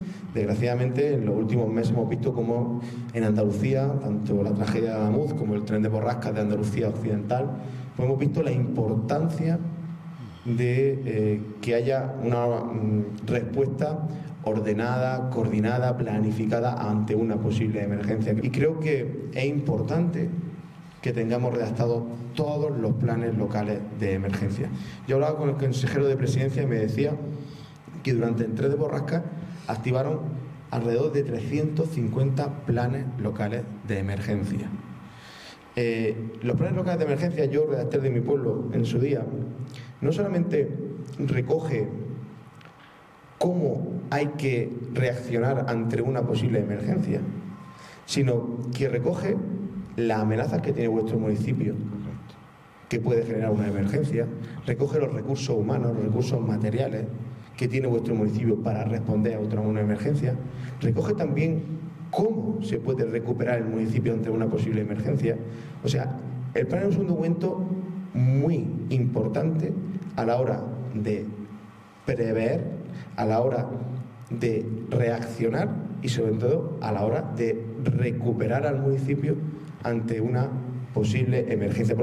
Esta mañana el presidente de Diputación, José Antonio García Alcaina, ha presentado esta medida en Dalías en un acto con representantes de los 53 municipios del área de influencia del Consorcio de Bomberos del Poniente